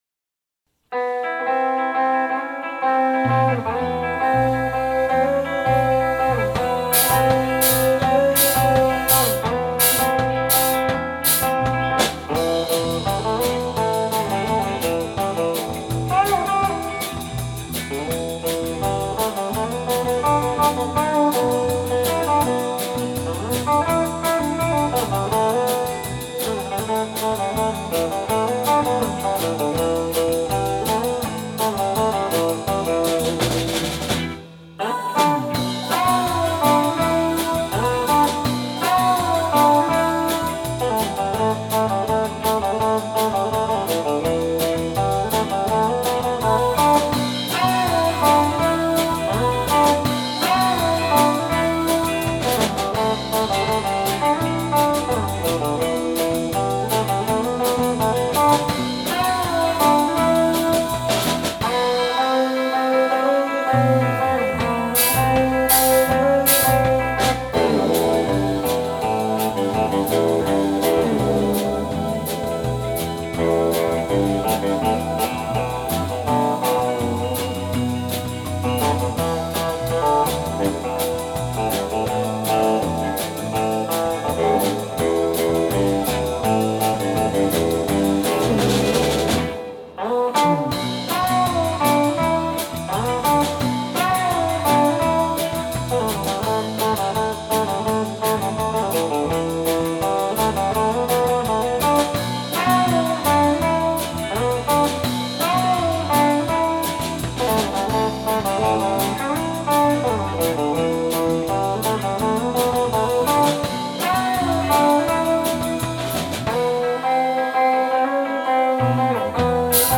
2019 X’mas Live